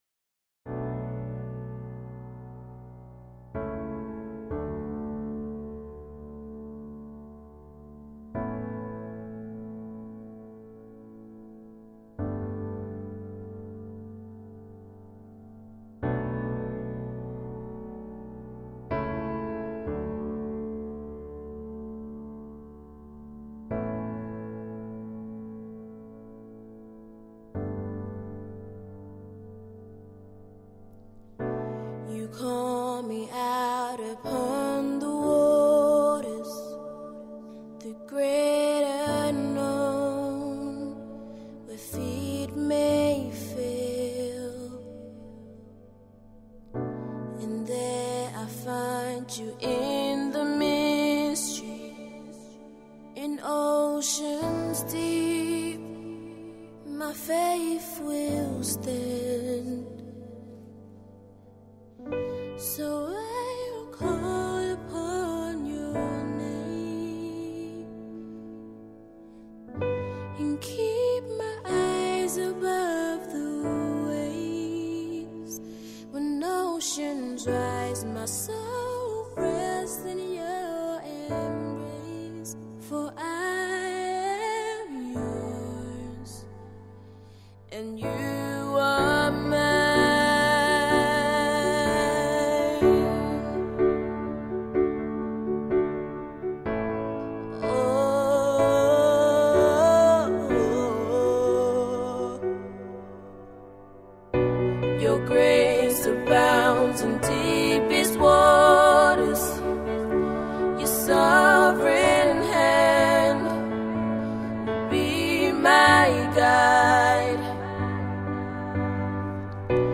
Gospel 2024